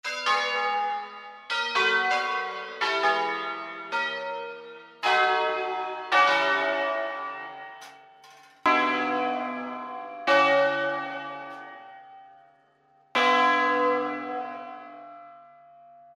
Entrando nello specifico di Bergamo, il suono ‘a scala’ si è sempre mosso sul principio del suono ‘al botto’, vale a dire producendo accordi invece di suoni alternati ed equidistanti:
Si parte chiamando II e III; la I viene chiamata subito dopo la terza.